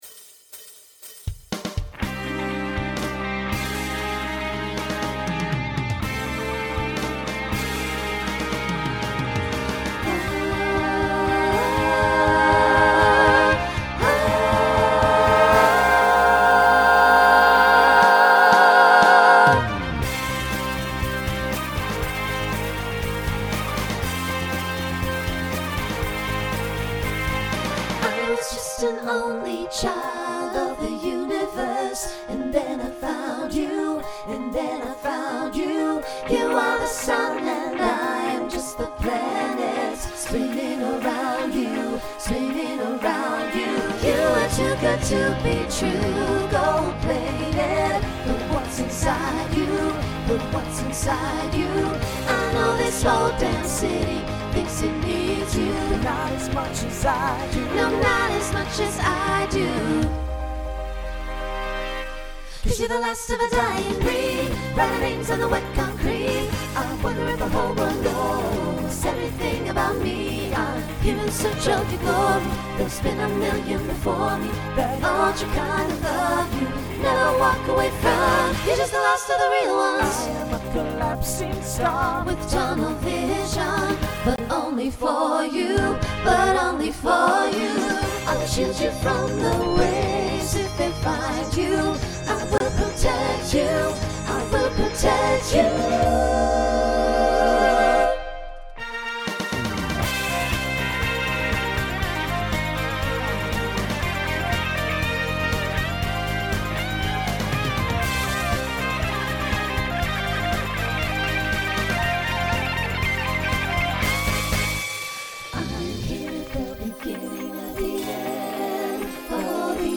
Voicing SATB
Genre Rock
2010s Show Function Opener